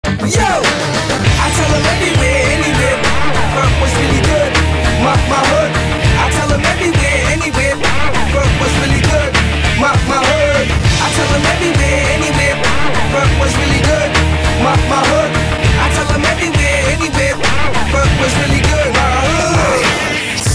• Качество: 256, Stereo
брутальные
Хип-хоп
качающие
OST